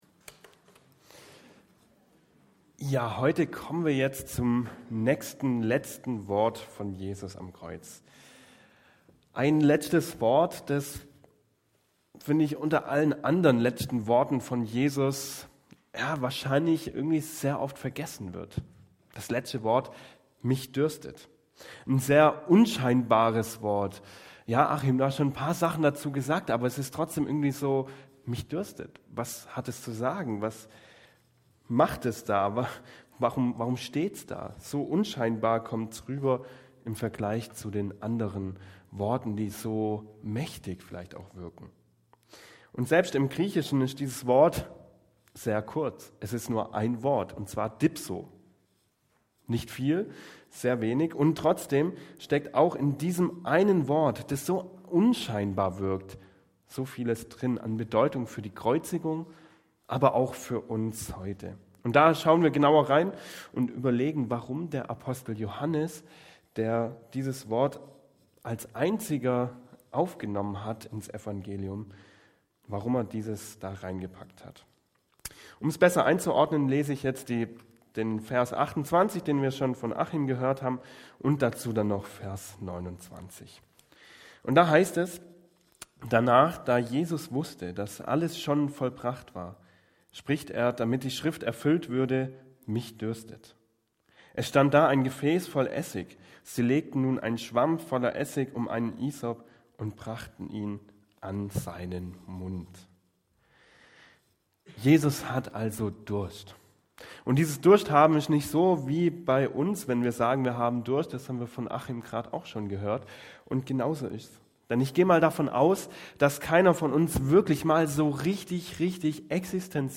Predigt 06.04.2025 - SV Langenau